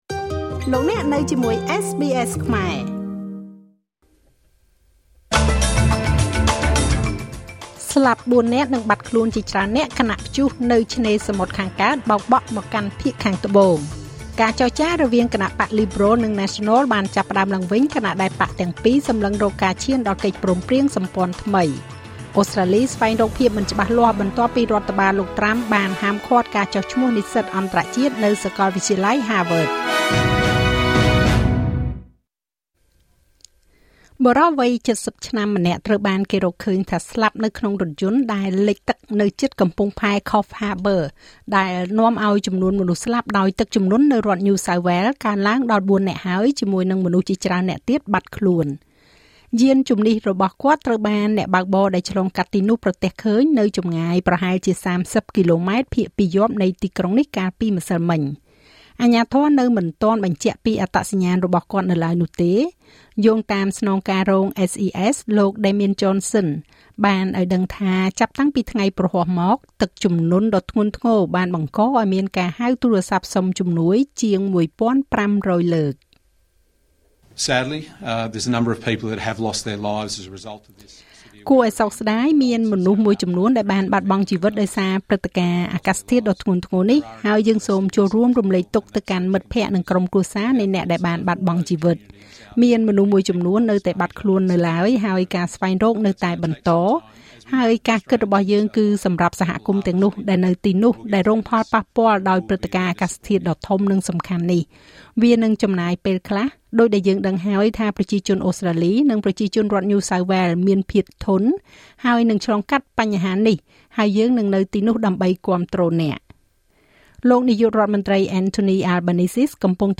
នាទីព័ត៌មានរបស់SBSខ្មែរ សម្រាប់ថ្ងៃសុក្រ ទី២៣ ខែឧសភា ឆ្នាំ២០២៥